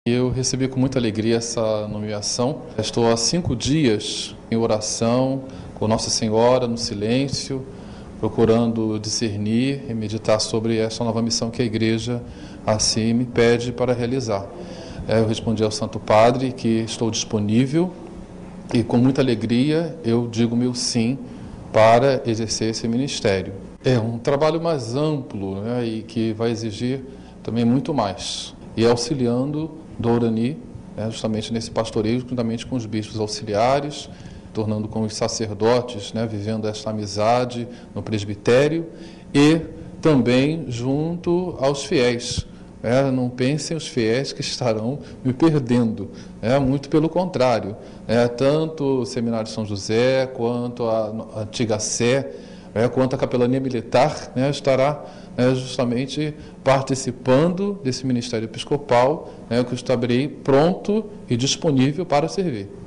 Ouça a entrevista do novo bispo concedida à Rádio Catedral do RJ nesta quarta-feira.